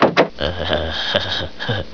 switch04.wav